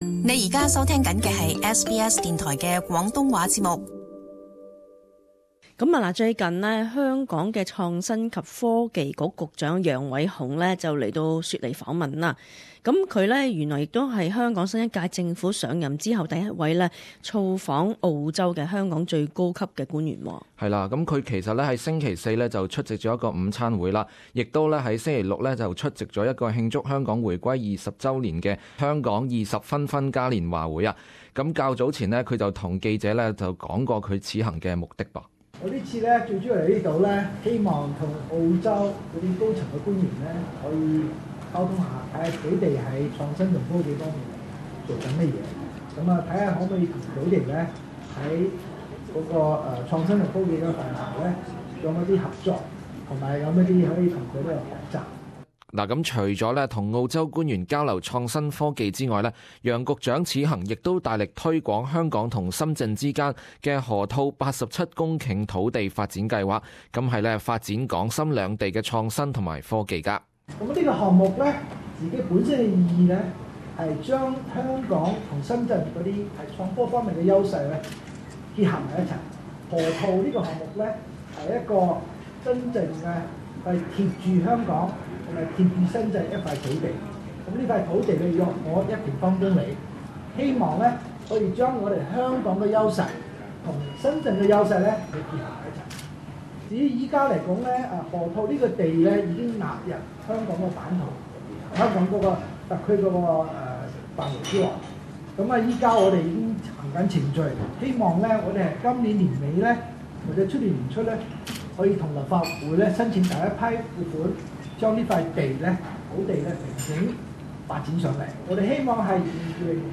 【社区专访】香港创科局局长杨伟雄访问雪梨